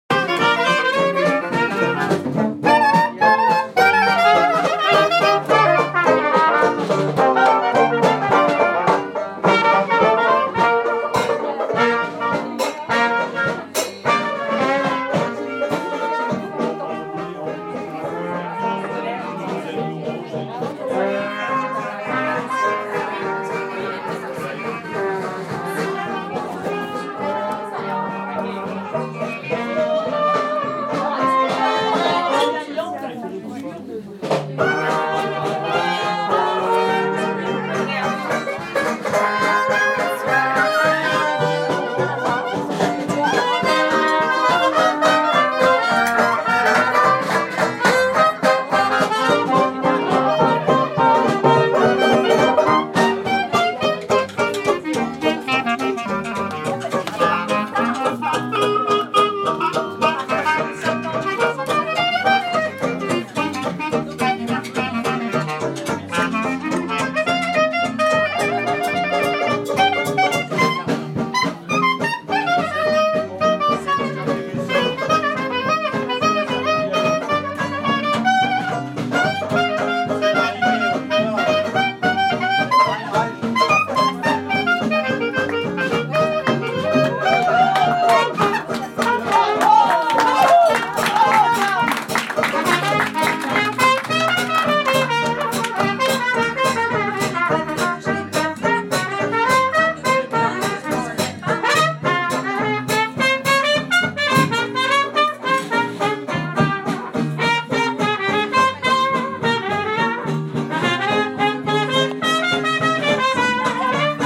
Jazz at Le Petit Journal jazz club Paris
Jazz Paris